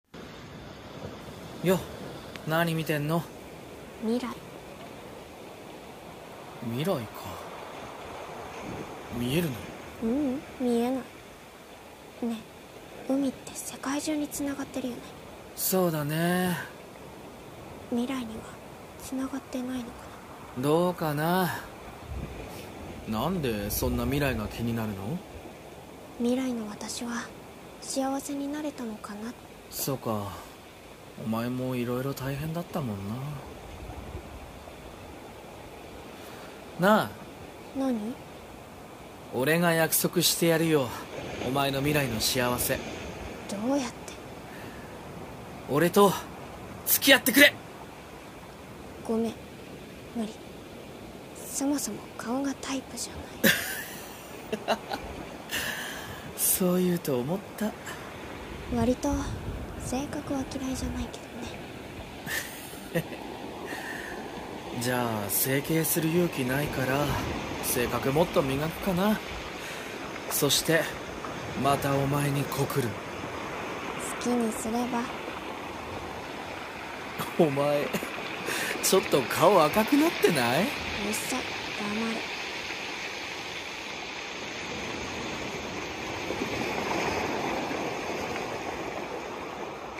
声劇【未来の音】※恋愛コラボ声劇